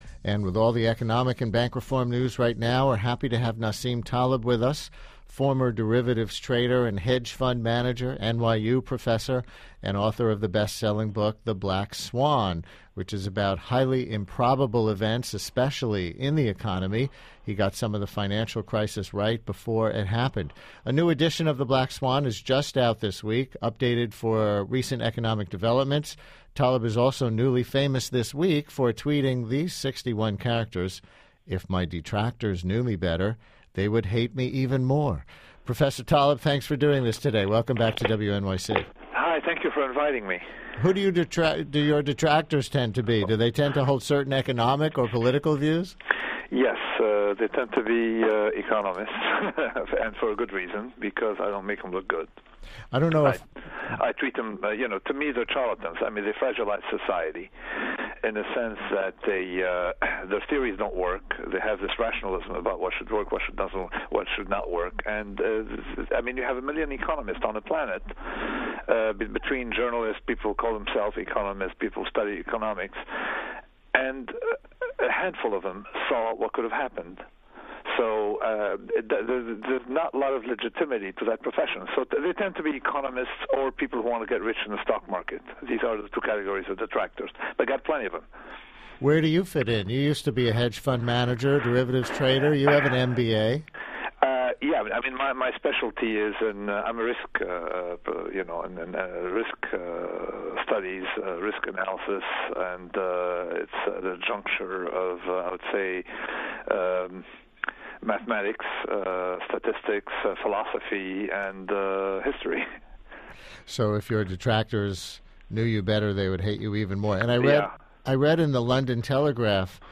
Nassim Taleb Interview – The Brian Lehrer Show 052110